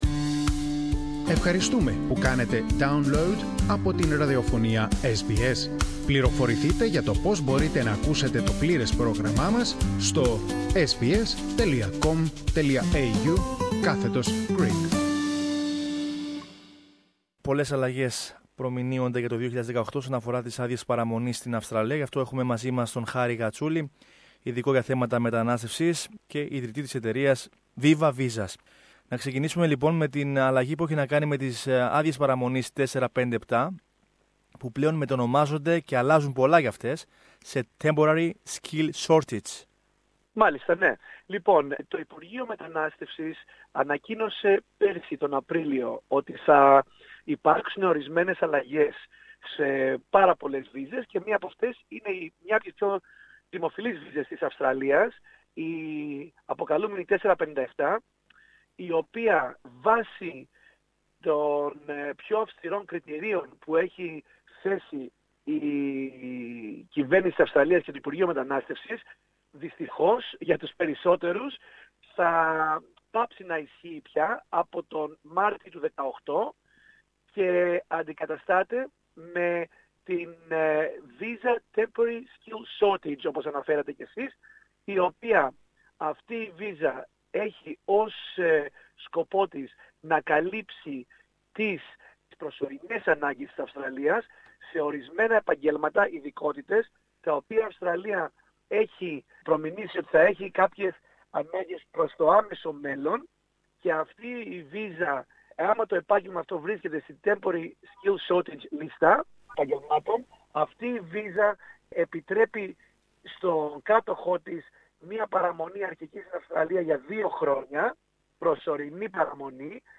Περισσότερα για τις αλλαγές αυτές ακούμε από τον μεταναστευτικό πράκτορα